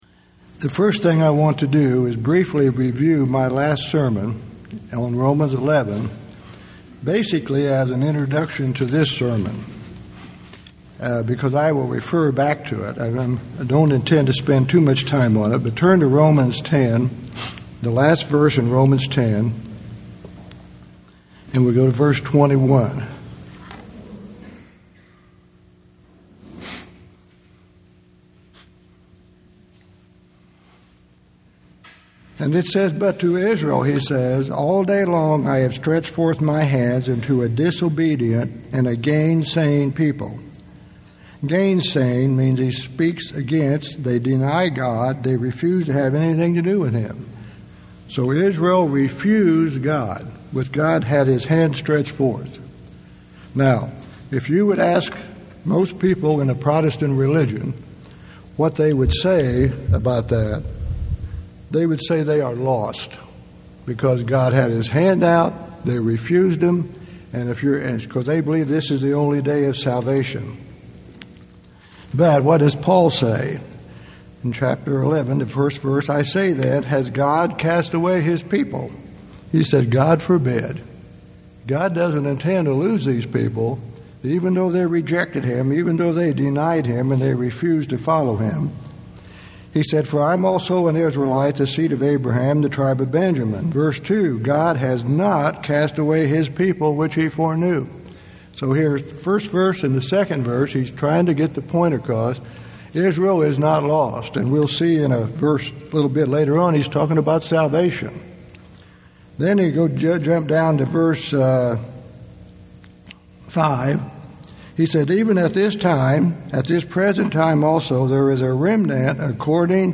Given in Ft. Wayne, IN
UCG Sermon